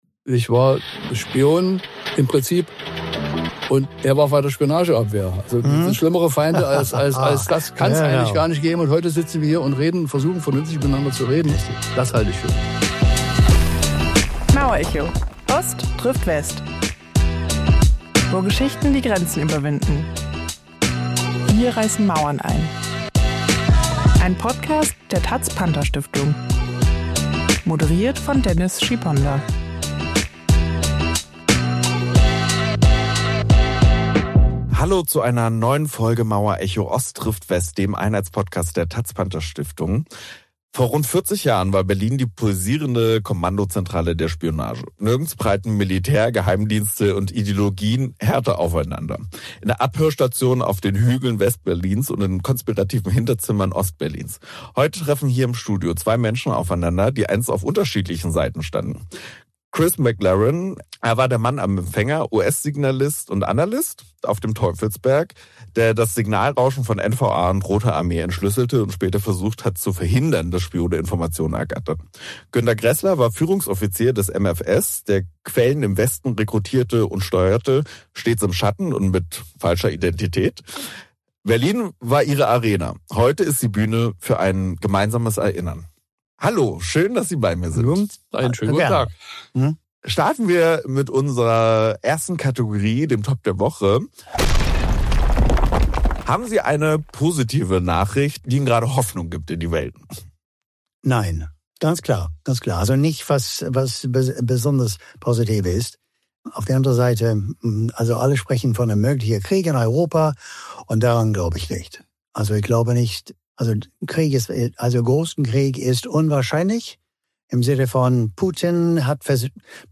Folge 40: Im Schatten des Kalten Krieges - Zwei verfeindete Spione erzählen ~ Mauerecho – Ost trifft West Podcast